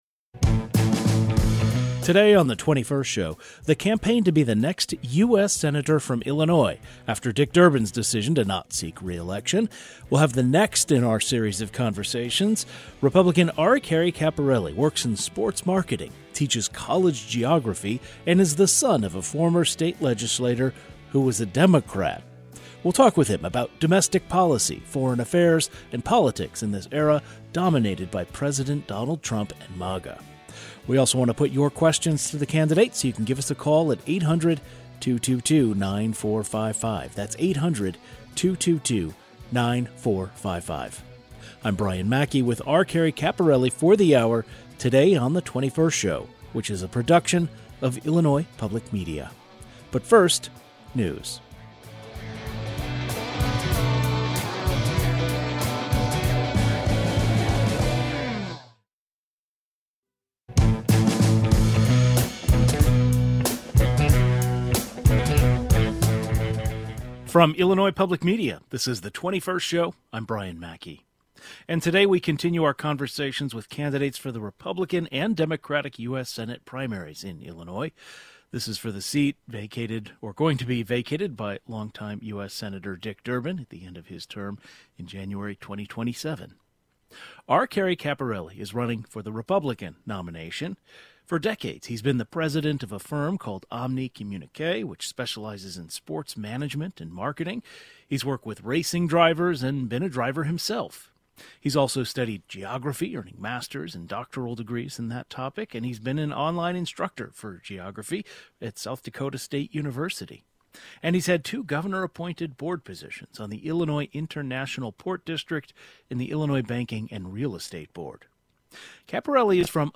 Another conversation with a candidate for the U.S. Senate